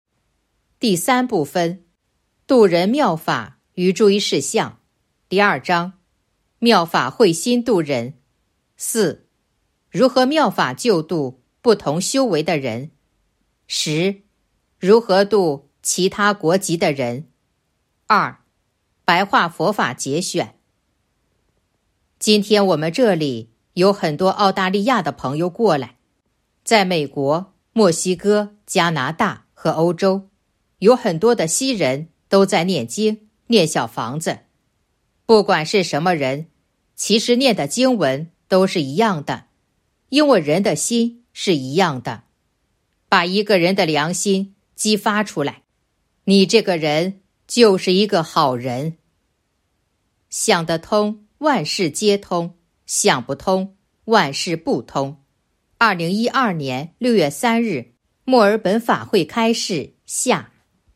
白话佛法节选《弘法度人手册》【有声书】